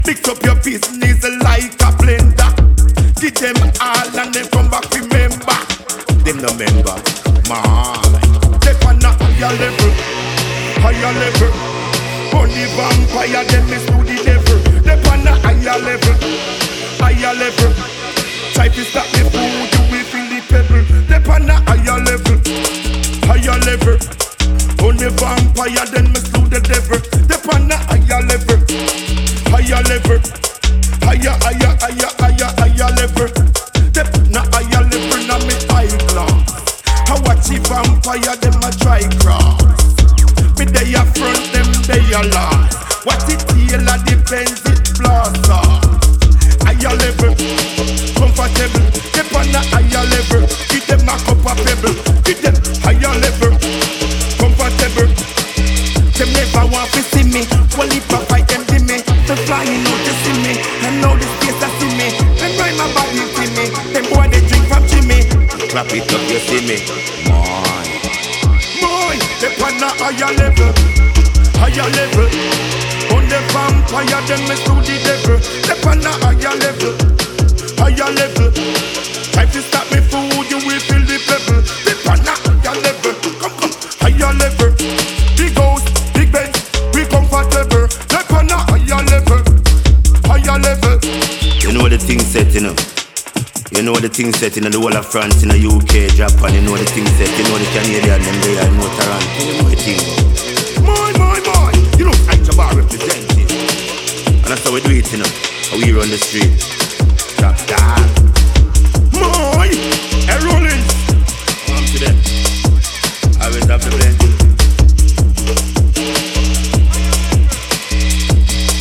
New Release Bass / Dubstep Dancehall